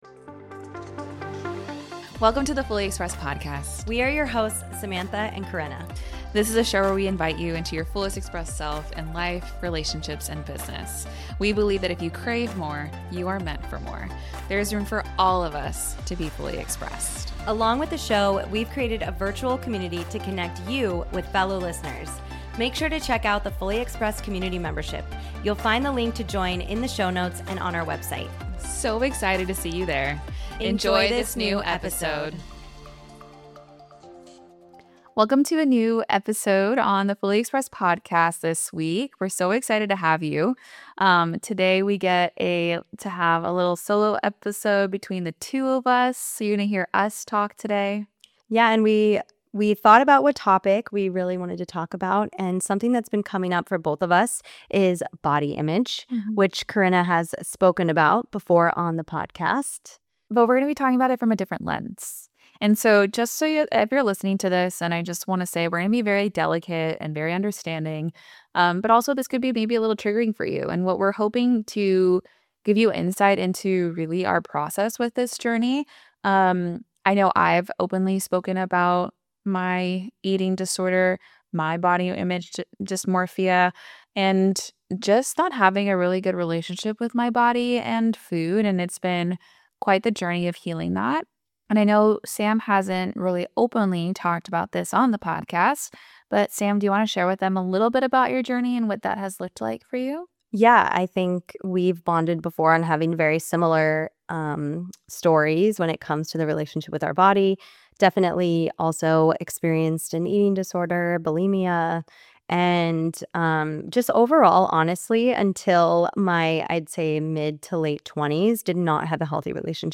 an honest and deeply personal conversation about healing from an eating disorder, redefining beauty, and stepping into a fully expressed, strong, and vibrant body.